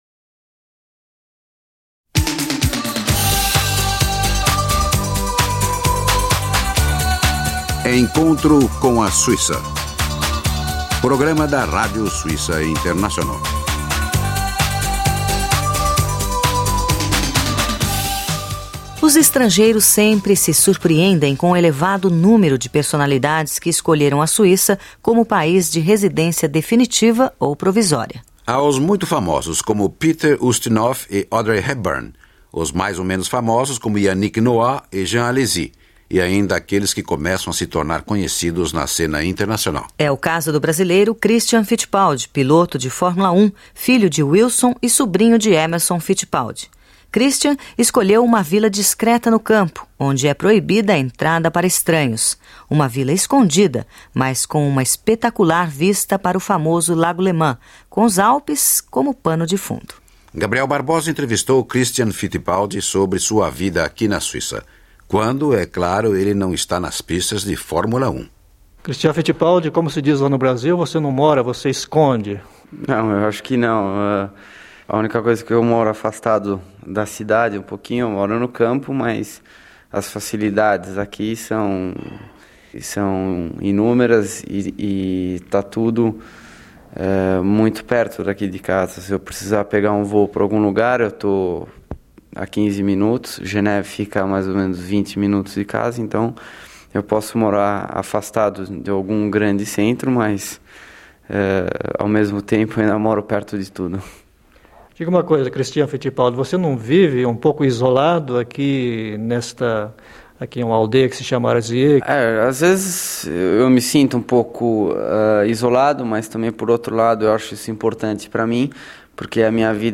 entrevistou o piloto Christian Fittipaldi, que na época era piloto de Fórmula 1 e morava na Suíça. Arquivo da Rádio Suíça Internacional.